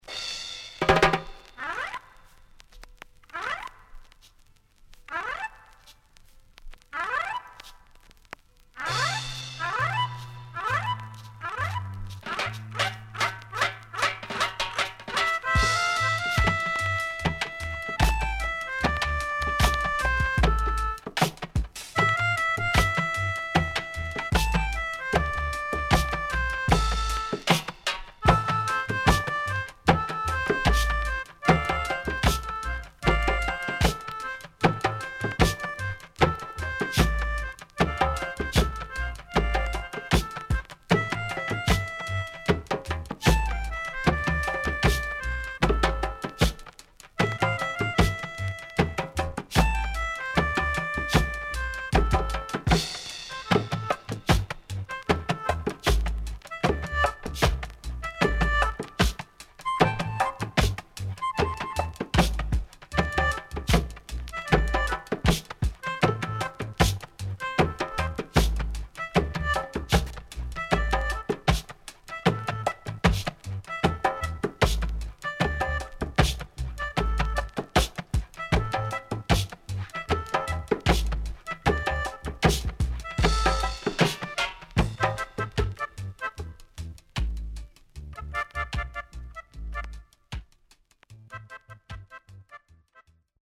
SIDE A:所々チリノイズがあり、少しプチパチノイズ入ります。